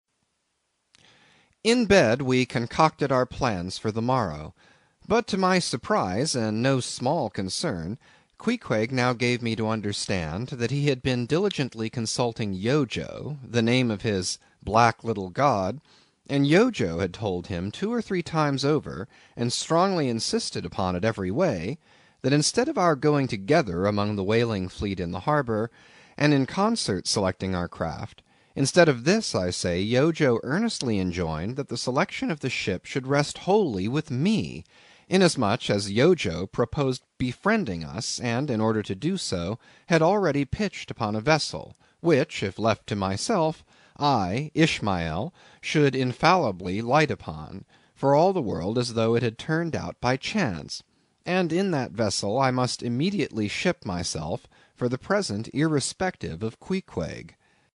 英语听书《白鲸记》第58期 听力文件下载—在线英语听力室